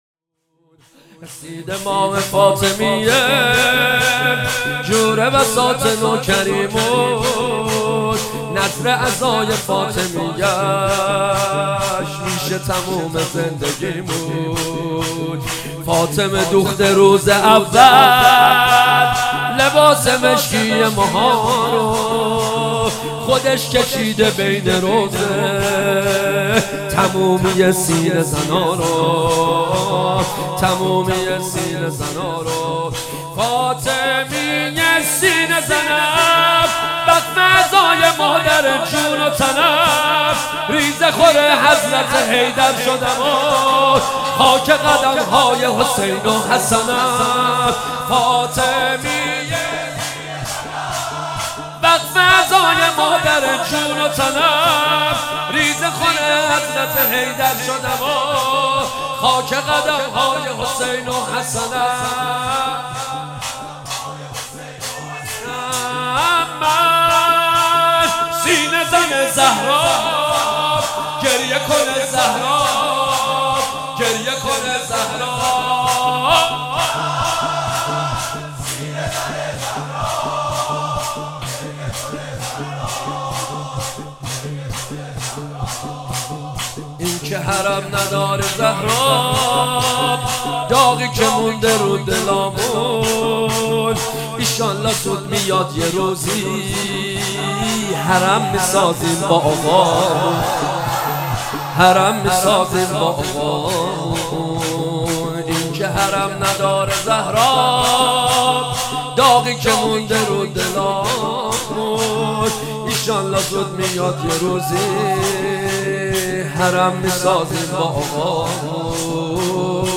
فاطمیه 96 - شور - رسیده ماه فاطمیه